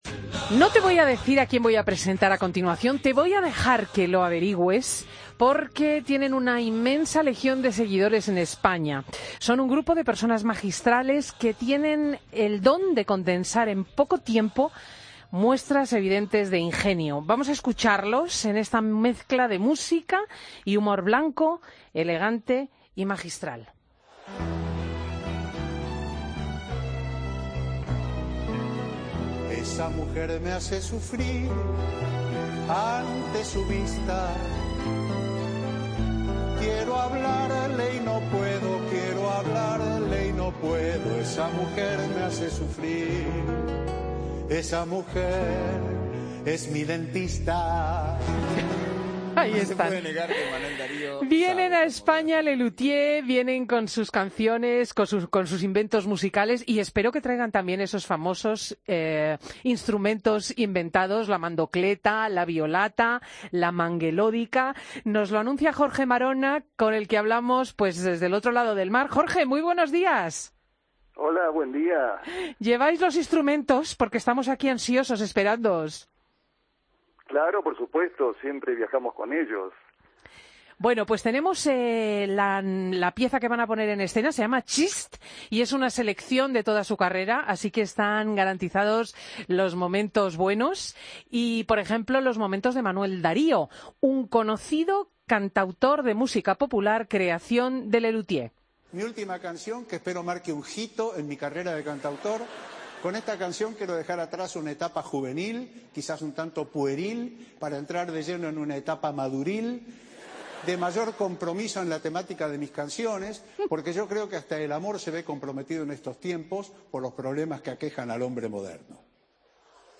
Escucha la entrevista a Jorge Maronna, humorista de 'Les Luthiers', en Fin de Semana en febrero de 2016